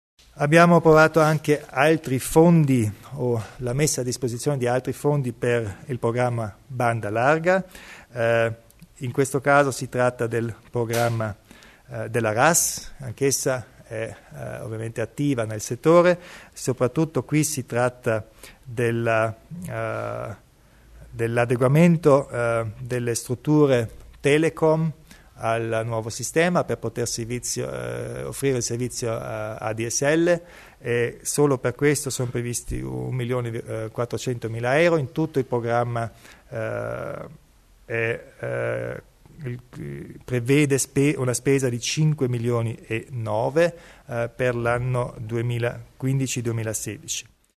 Il Presidente Kompatscher spiega l'impegno nel settore della banda larga